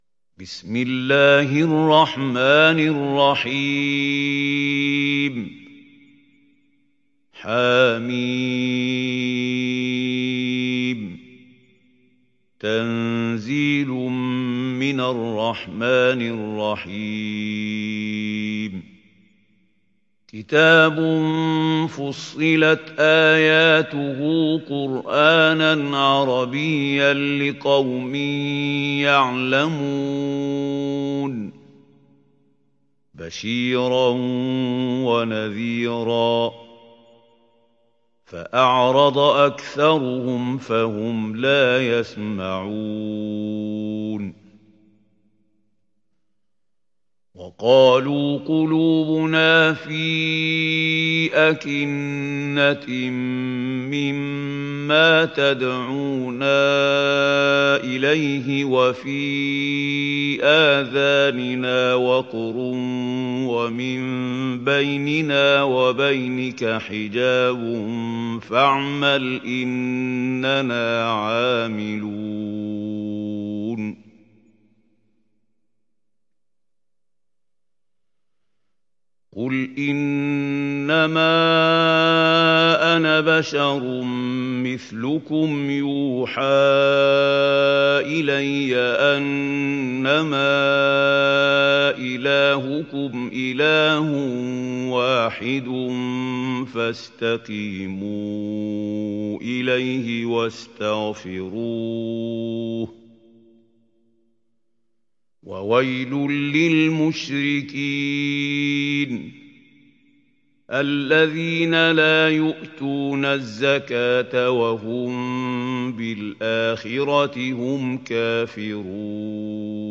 Surat Fussilat mp3 Download Mahmoud Khalil Al Hussary (Riwayat Hafs)